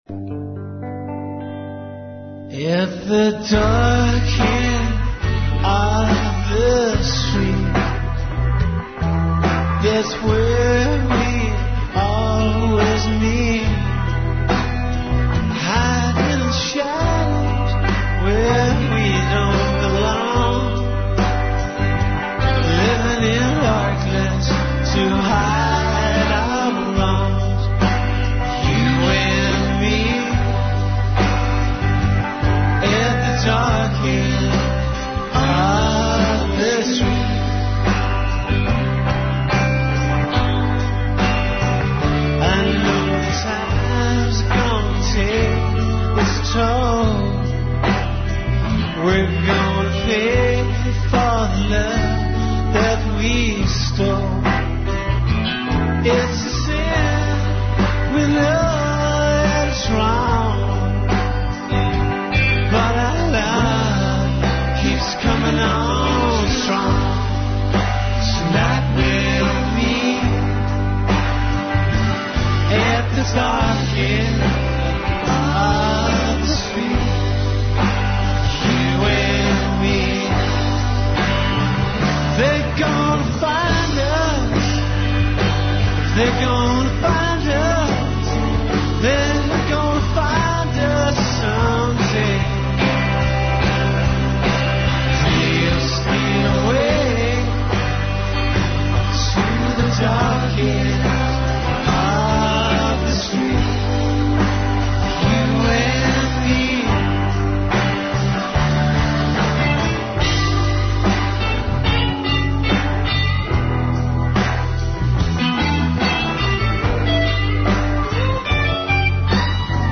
Два велика музичка састава гости су емисије, Репетитор и Атеист реп.